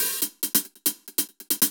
Index of /musicradar/ultimate-hihat-samples/140bpm
UHH_AcoustiHatA_140-03.wav